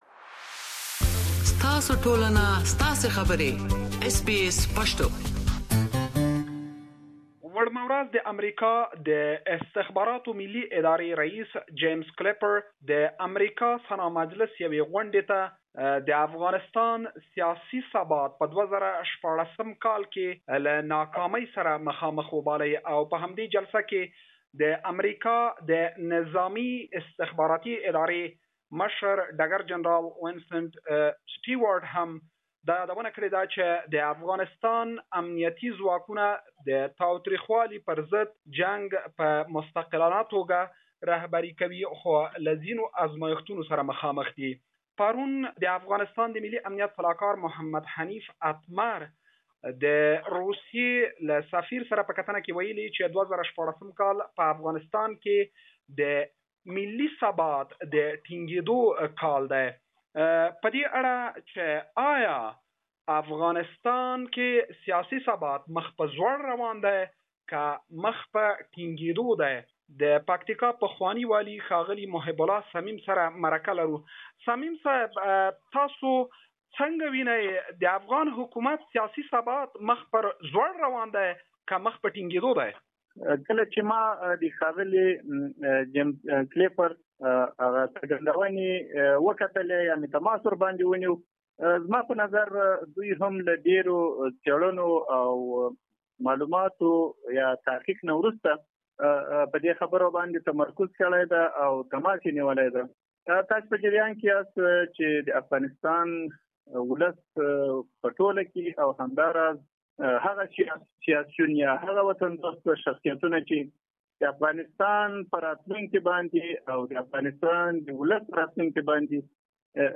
The Director of US National Intelligence Mr James Clapper says that US will face significant challenges in year 2016; Mr Clapper also added that Afghan government will politically collapse.Are Mr Clappers recent remarks bring instability in Afghanistan and are they seen as reality within Afghan unity government. We have interviewed former governor of Paktika province Mr Mohebullah Samim.